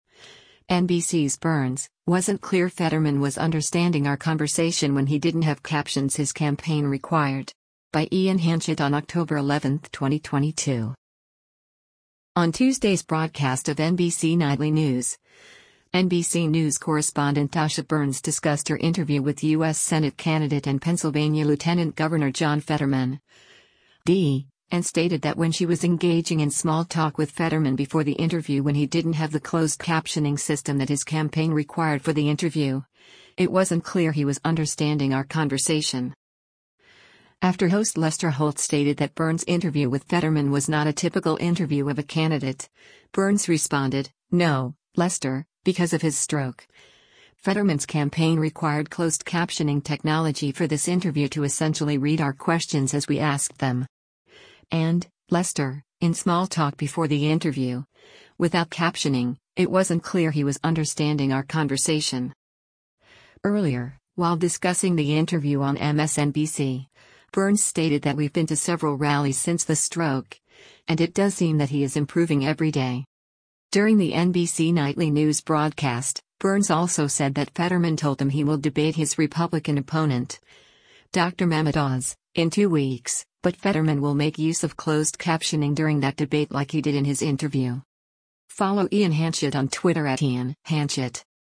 On Tuesday’s broadcast of “NBC Nightly News,” NBC News Correspondent Dasha Burns discussed her interview with U.S. Senate candidate and Pennsylvania Lt. Gov. John Fetterman (D) and stated that when she was engaging in small talk with Fetterman before the interview when he didn’t have the closed captioning system that his campaign “required” for the interview, “it wasn’t clear he was understanding our conversation.”